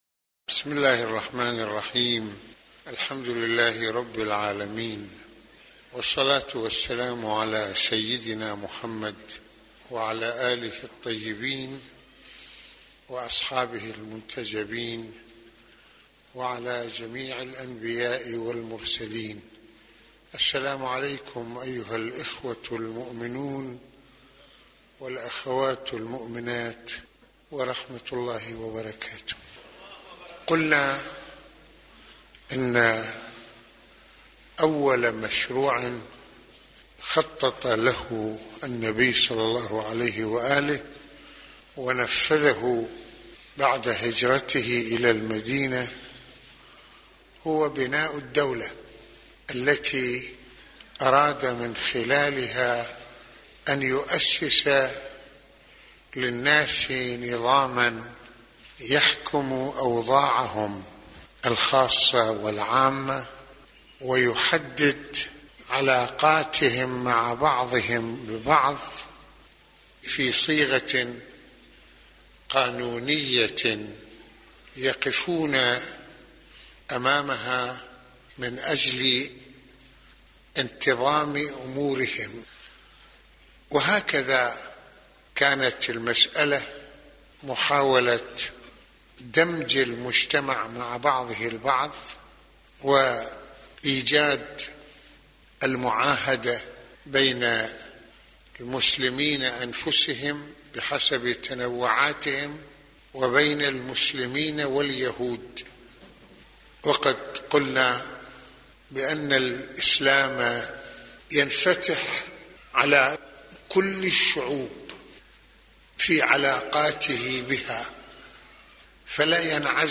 - يتحدث سماحة المرجع السيد محمد حسين فضل الله (رض) في هذه المحاضرة العاشورئية عن انفتاح الإسلام على كل الشعوب ومرونته في التعامل معها وتأكيده على الوفاء بالعهود والعقود الخاصة والعامة والانطلاق لحفظ كل أوضاعه وعلاقاته وأنظمته على أساس العدل مع الذات والله والناس...